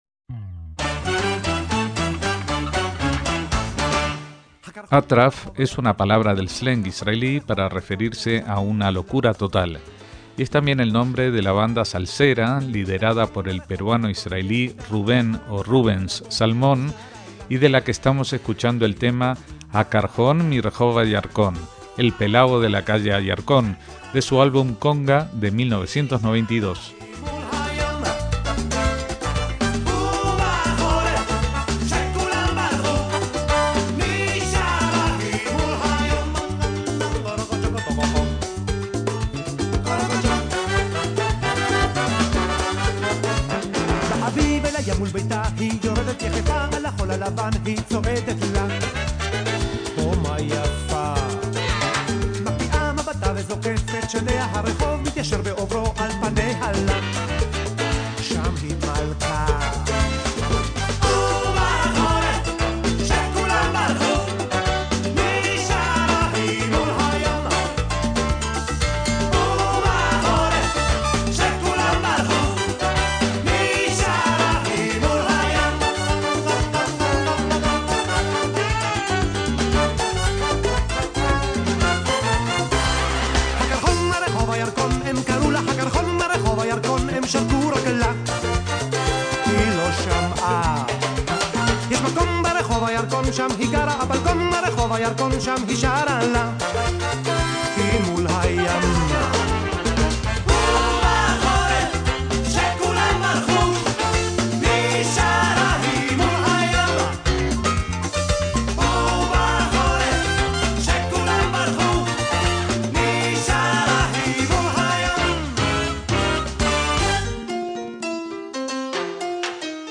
rock latino